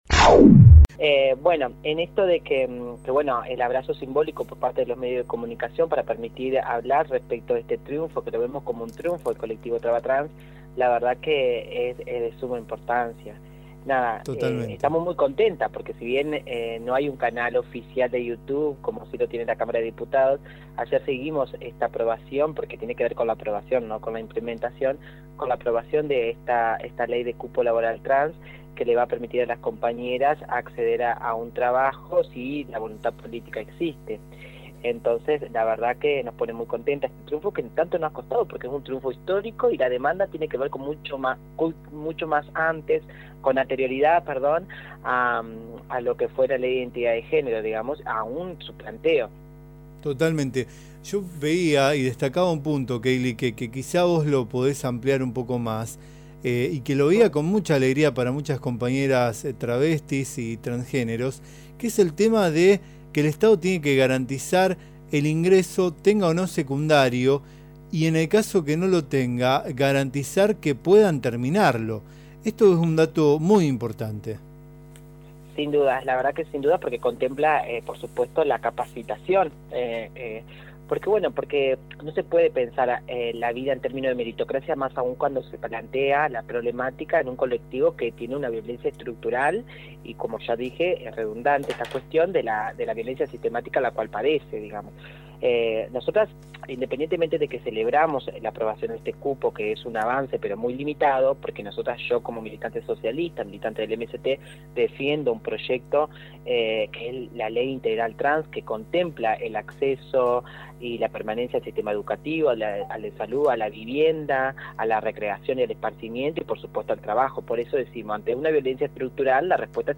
en una nota radial de AM980